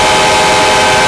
5 chime horn 4b.ogg